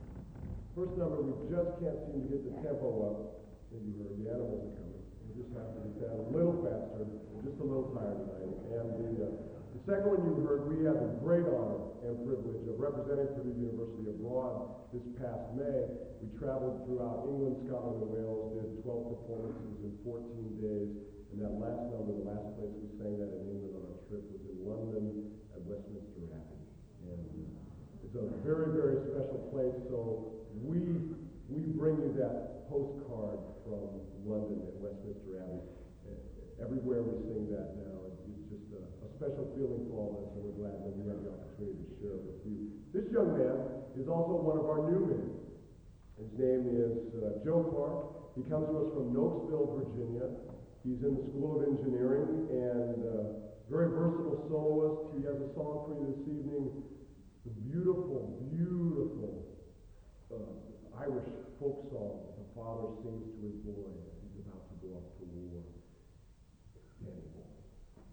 Collection: South Bend 1990